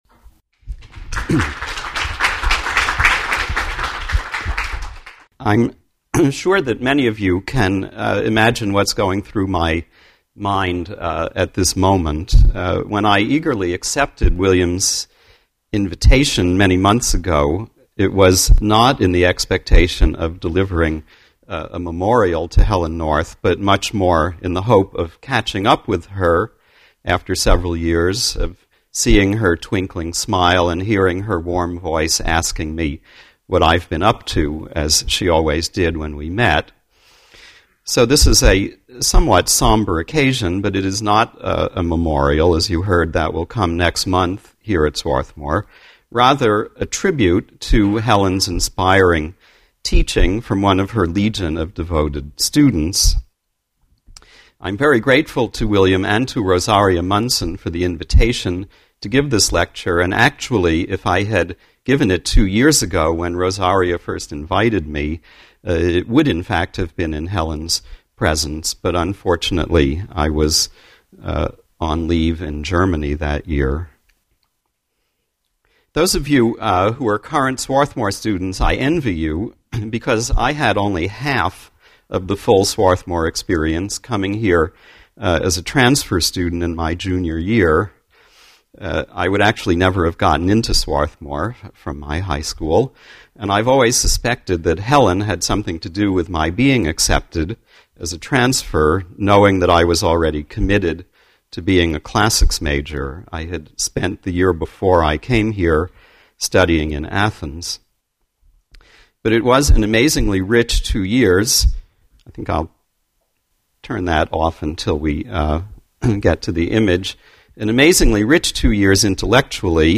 depicting the personification of soteria at the 12th annual Helen North Lecture.